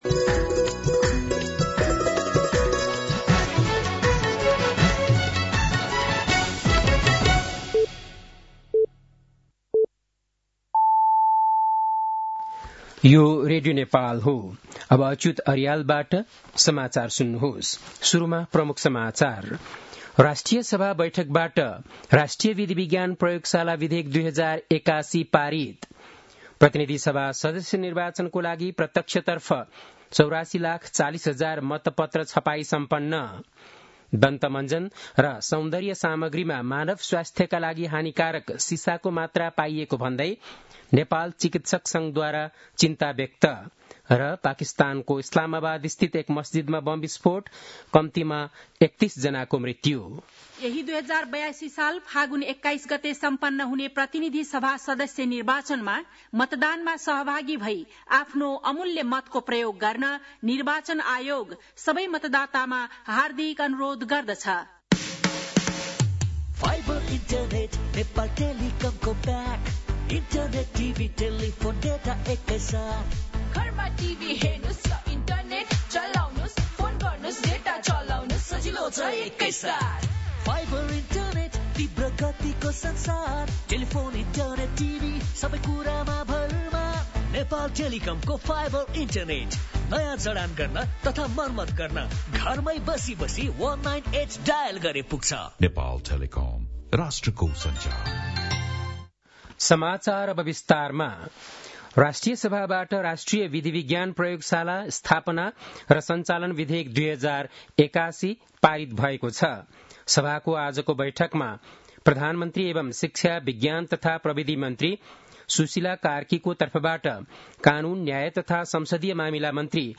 बेलुकी ७ बजेको नेपाली समाचार : २३ माघ , २०८२